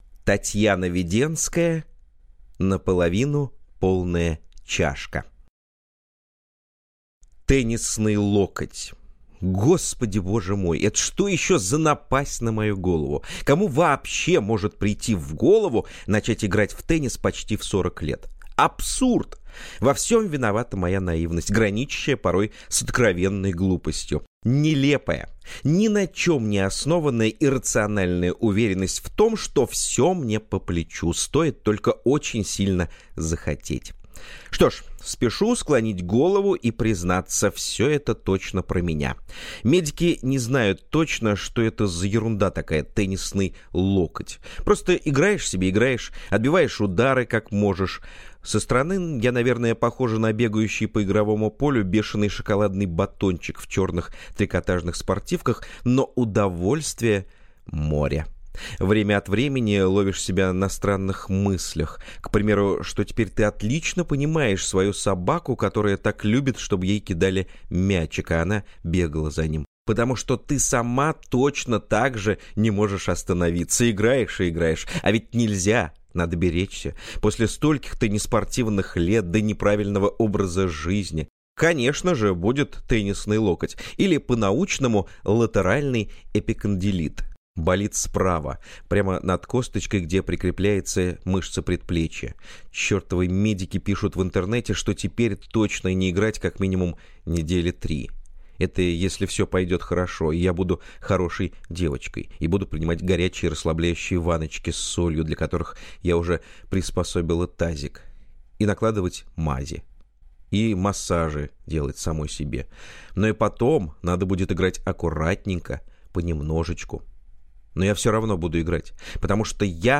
Аудиокнига Наполовину полная чашка | Библиотека аудиокниг